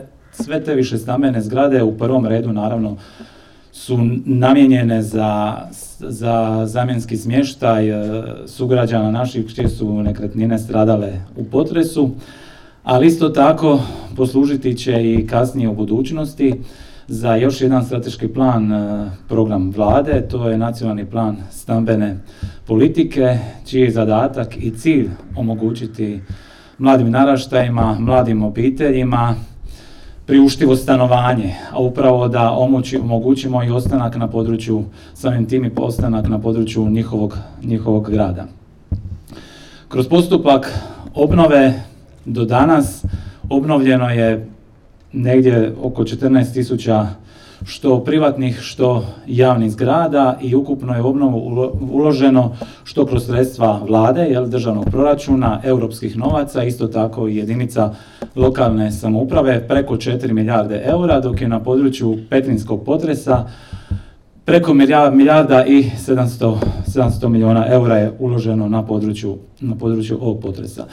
Državni tajnik Tonči Glavinić istaknuo je kako Vlada RH, u suradnji sa Sisačko-moslavačkom županijom i Gradom Siskom, čini sve kako bi obnovila i izgradila nove zgrade, ali i demografski te gospodarski revitalizirala područje cijele županije.